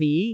speech
cantonese
syllable
pronunciation